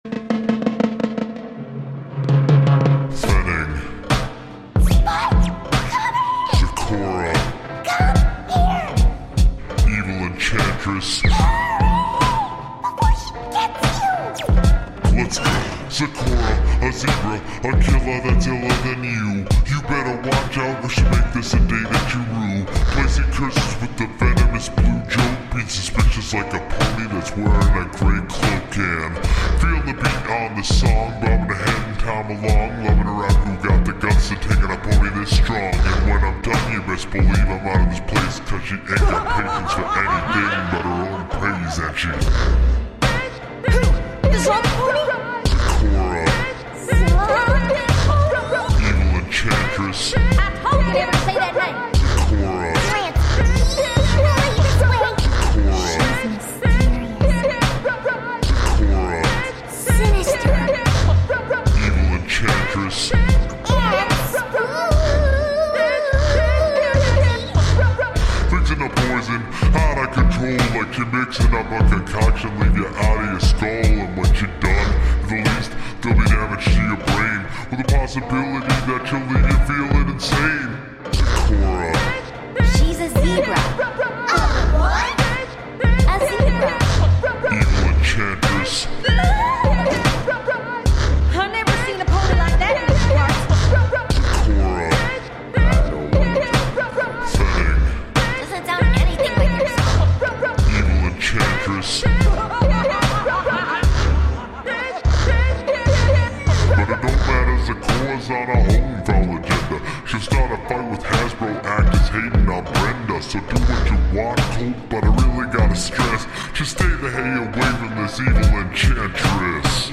lightly chopped & screwed hip-hop
genre:remix